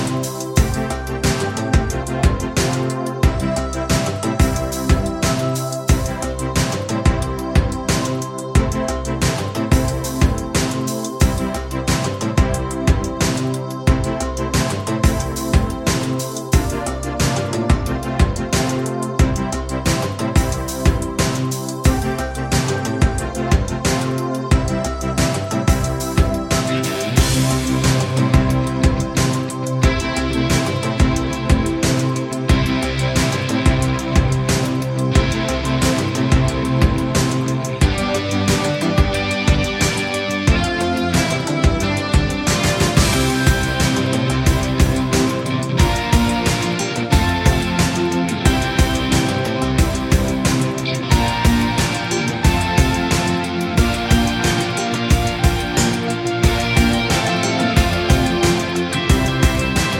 Minus Sax Pop (1980s) 4:09 Buy £1.50